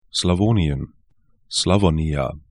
Aussprache
Slawonien sla'vo:nĭən Slavonija